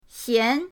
xian2.mp3